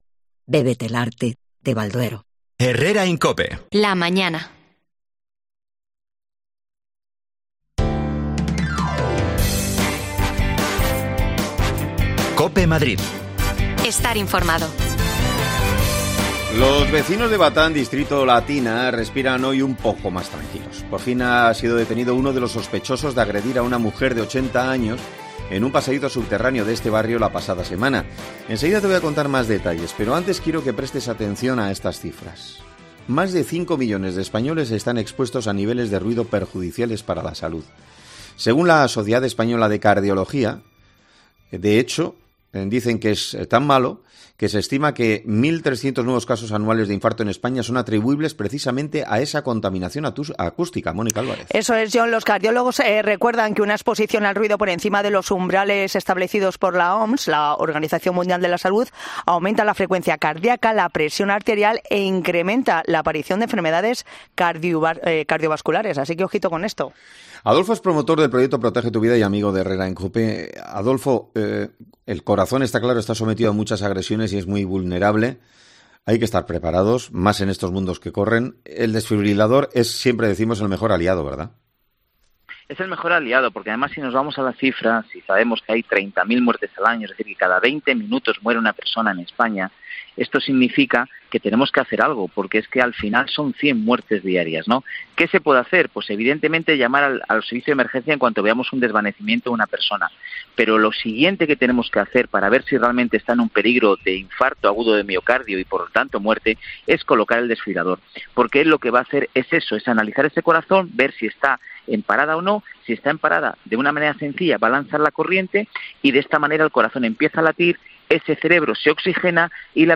Los vecinos de Batán respiran hoy un poco más tranquilos tras la detención de un individuo sospechoso de cometer numerosos robos en los pasadizos de la A.5. Hablamos con ellos